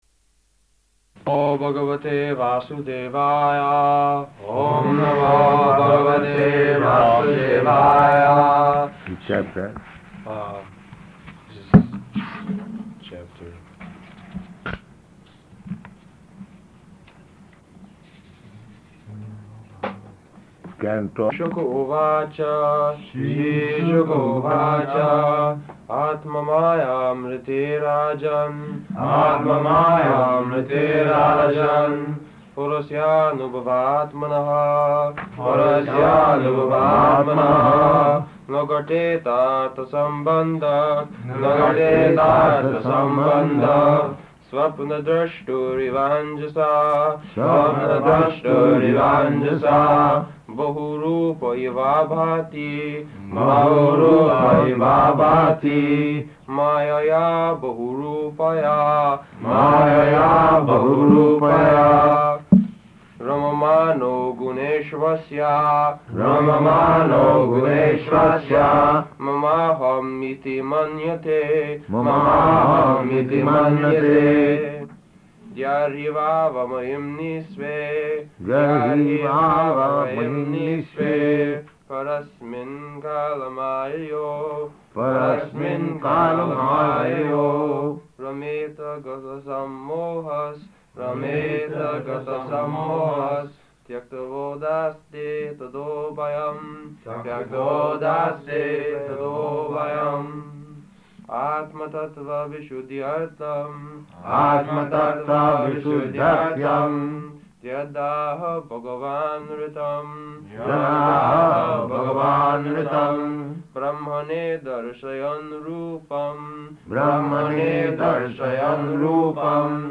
April 27th 1972 Location: Tokyo Audio file